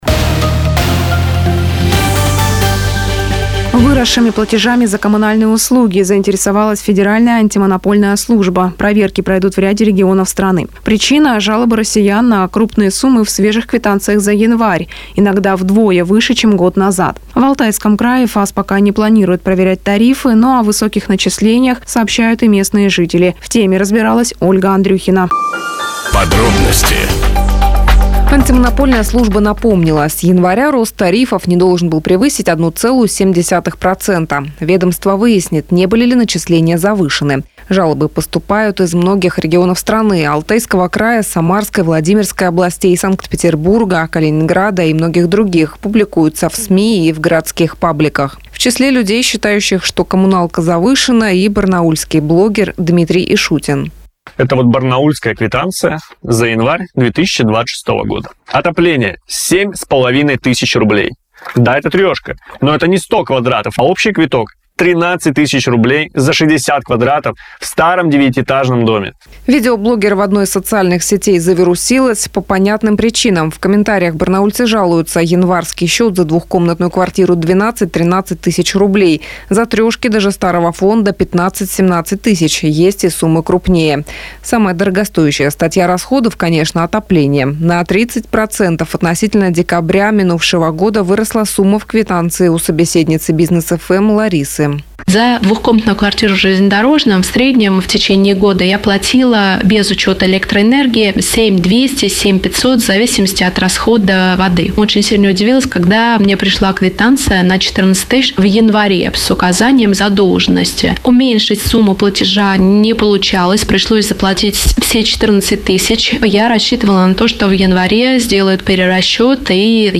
Сюжет на Business FM (Бизнес ФМ) Барнаул